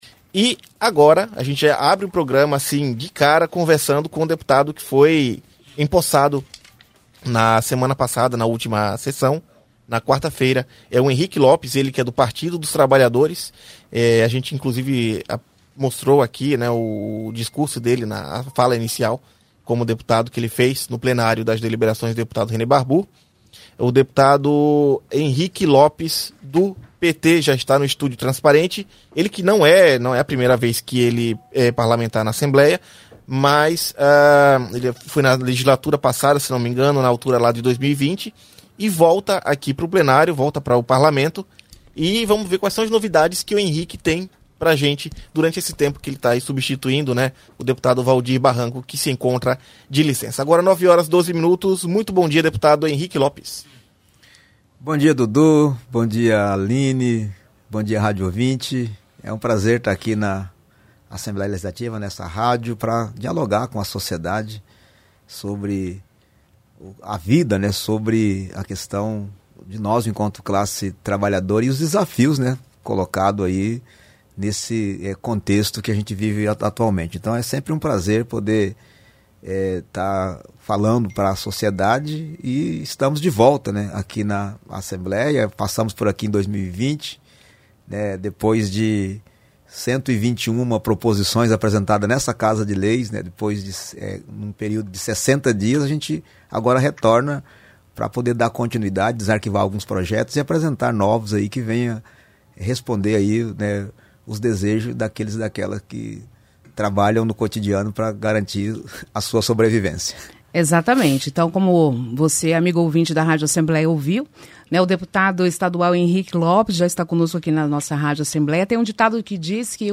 Programa Painel Entrevista: Deputado Henrique Lopes